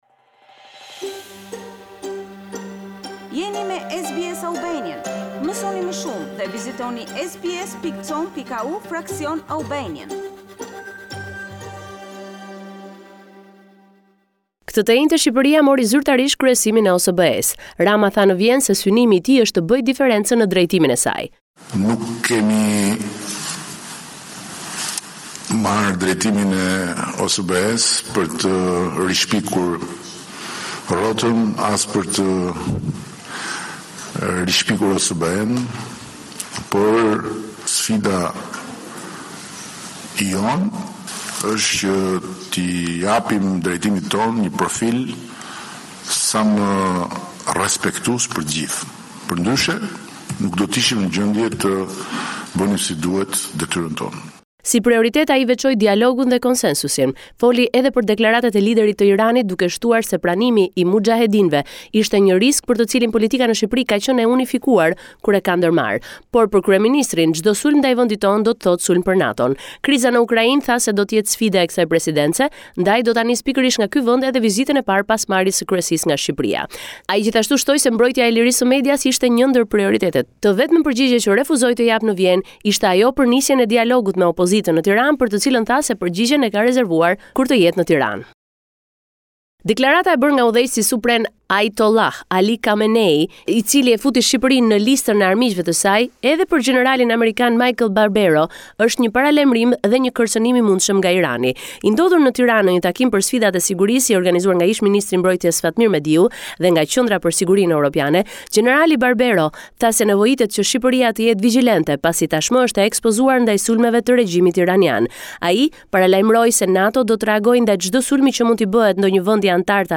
This is a report summarising the latest developments in news and current affairs in Albania